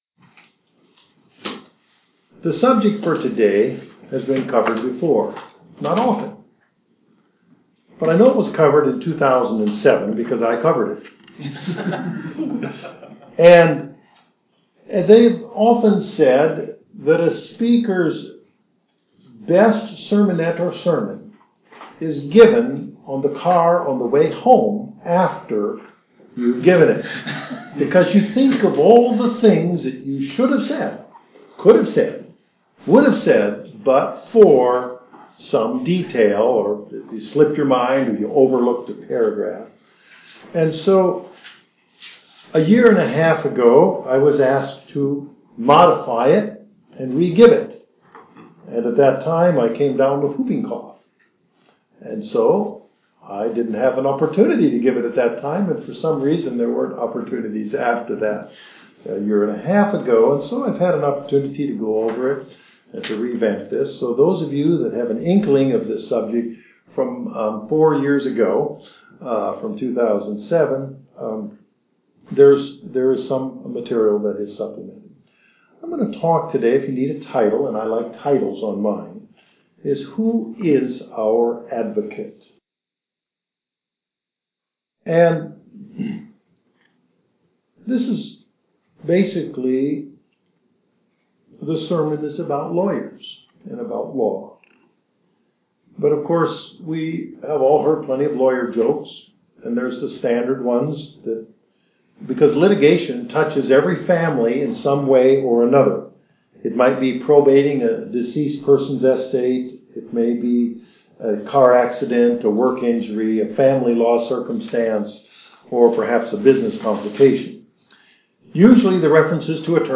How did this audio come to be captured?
Given in Northwest Arkansas